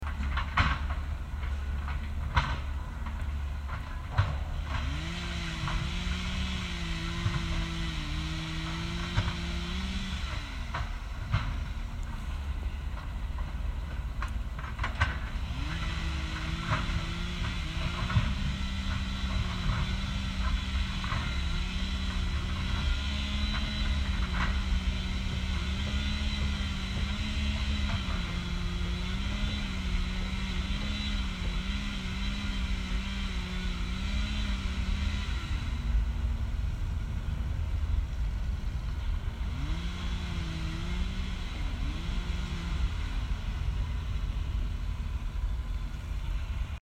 It’s a sound dreaded by conservationists the world over.
That’s what I experienced on Sunday on my local patch.
With chainsaws roaring and bulldozers belching out dark smoke as they demolished trees and shrubs, what I had planned to be a relaxing walk around the local patch on Sunday afternoon instead turned into a time for sober reflection about what this tiny space had given me over the past four years.